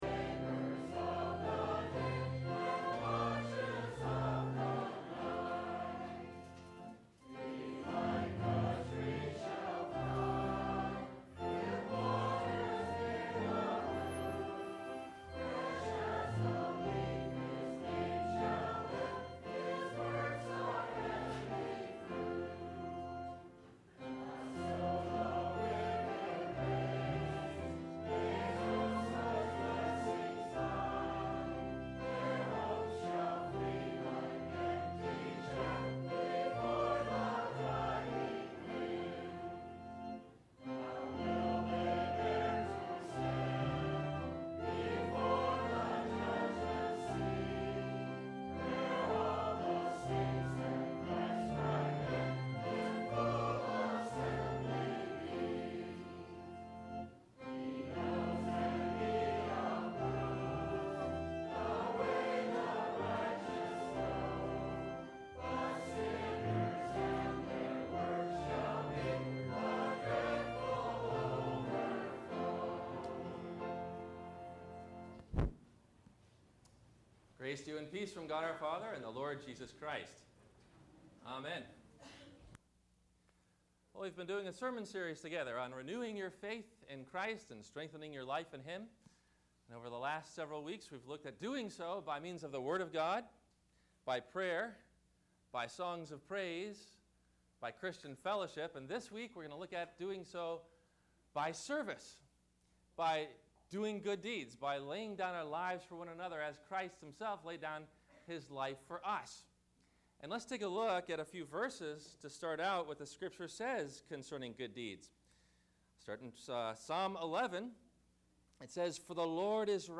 Gods Love For Sinners – Sermon – February 12 2012